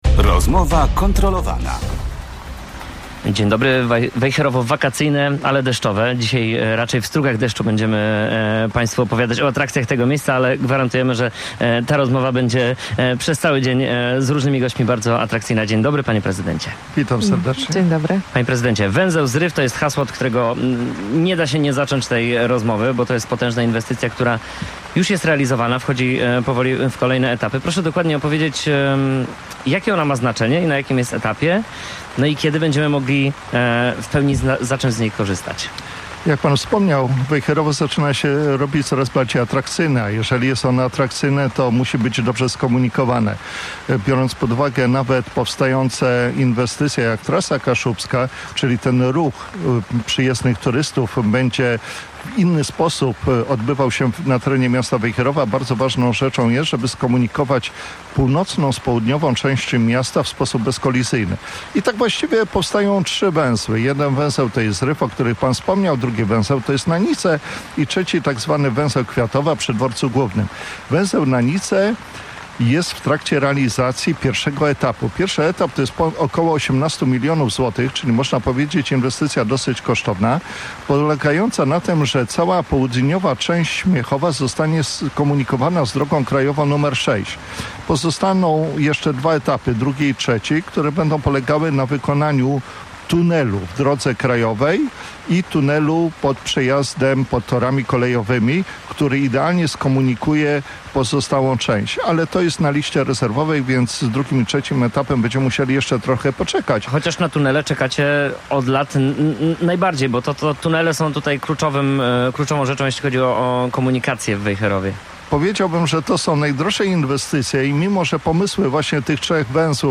Gość Rozmowy Kontrolowanej w Radiu Gdańsk wyjaśniał, co dadzą mieszkańcom i turystom nowe węzły komunikacyjne.